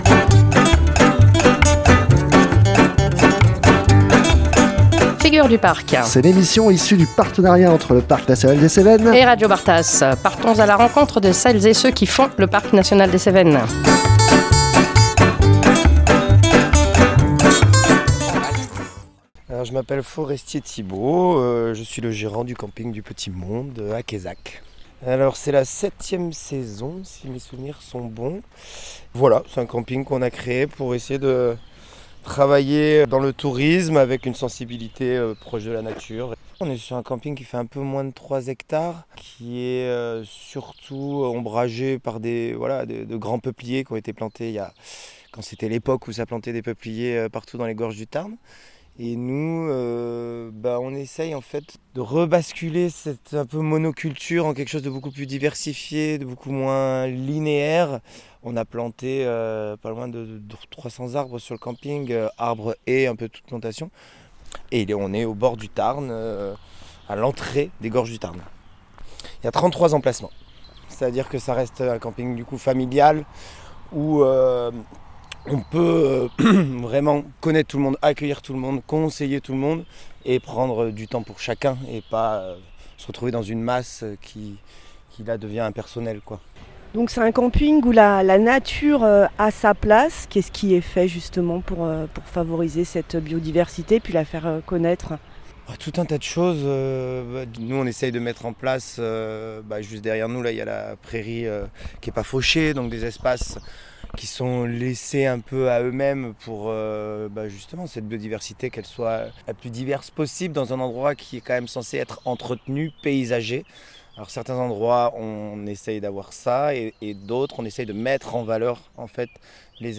nous sommes allés au bord de la rivière afin de découvrir les petites bêtes qui la peuple !